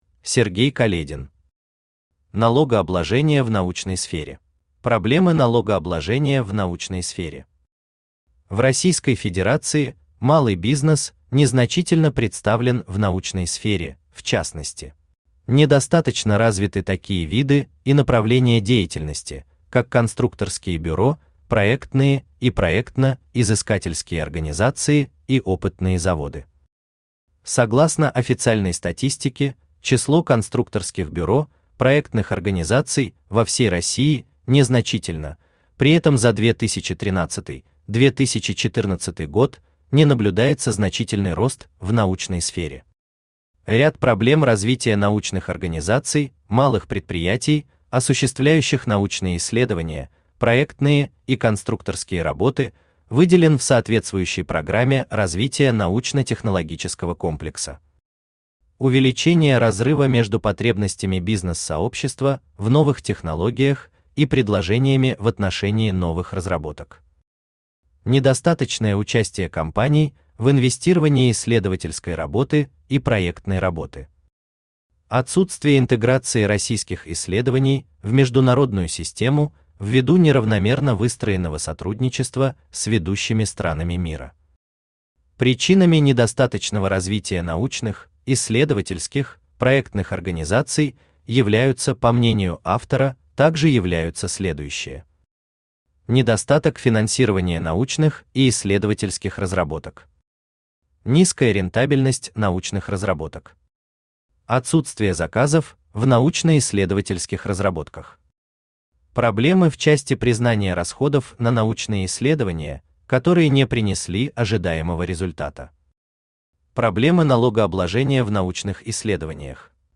Аудиокнига Налогообложение в научной сфере | Библиотека аудиокниг
Aудиокнига Налогообложение в научной сфере Автор Сергей Каледин Читает аудиокнигу Авточтец ЛитРес.